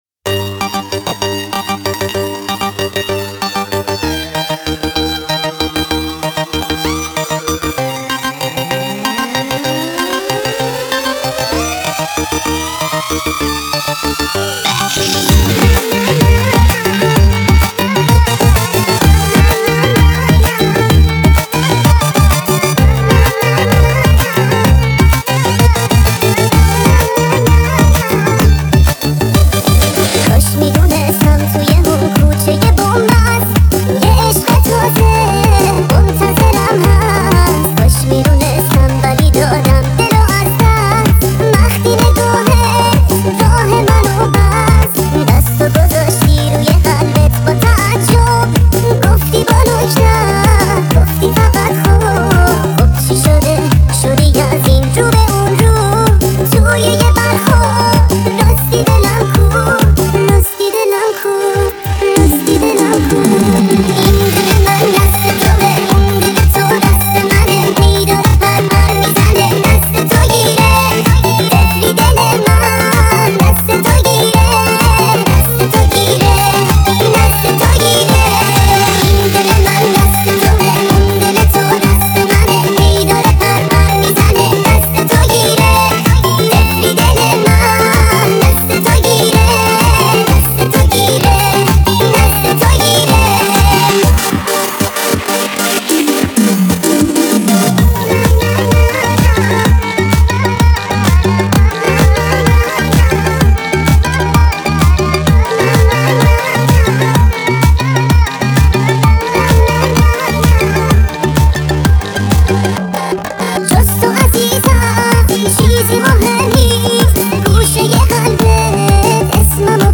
ریمیکس با صدای زن